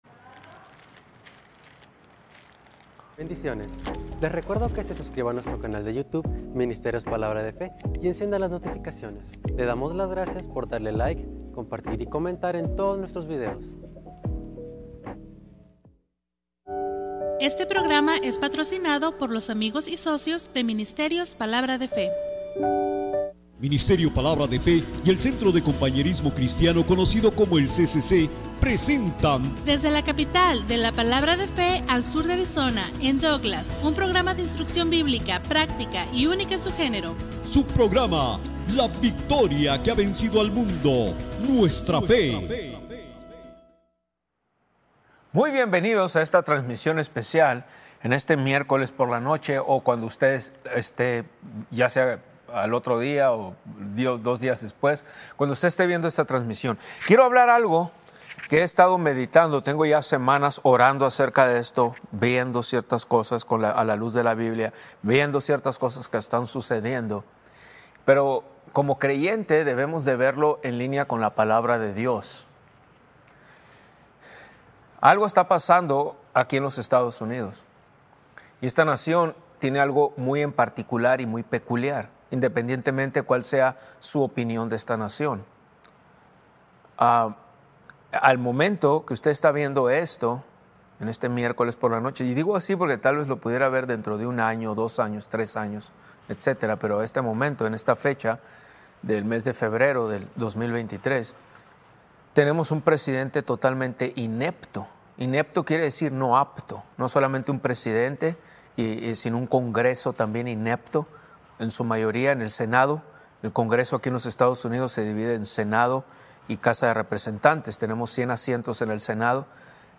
Maestro